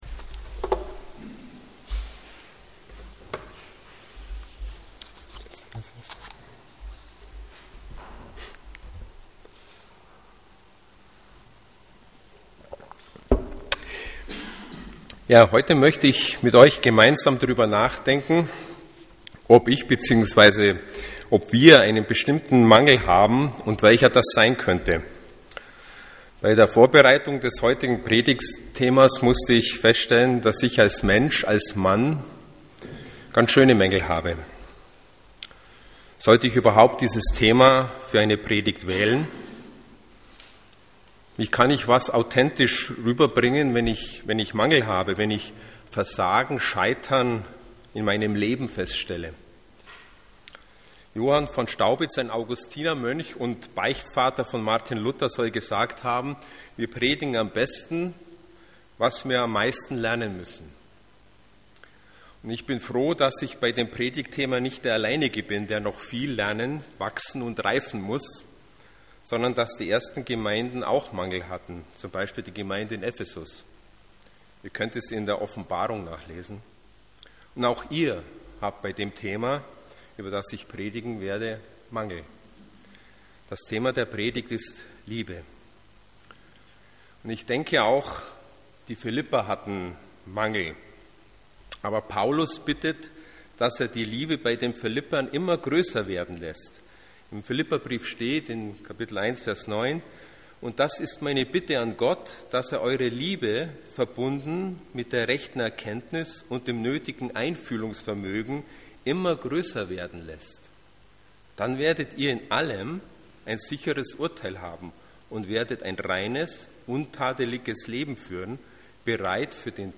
EFG Erding – Predigten